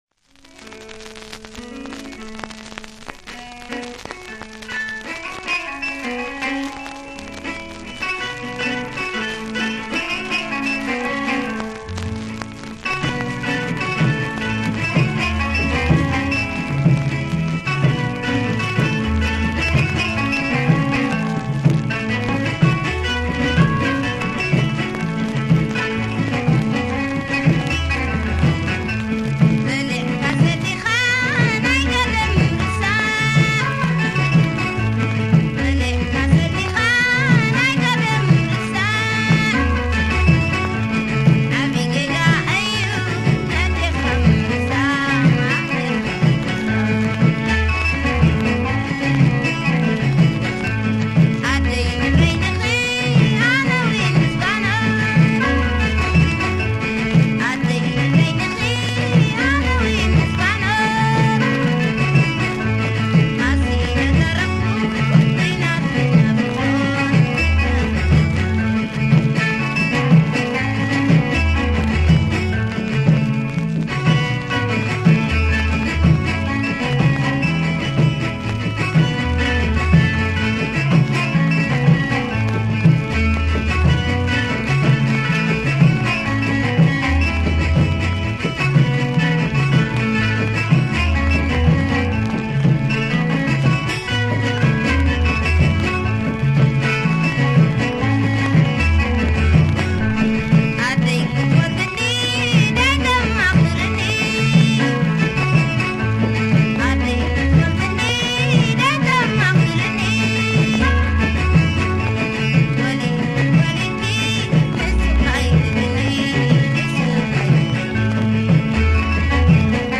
krar